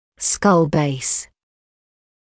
[skʌl beɪs][скал бэйс]основание черепа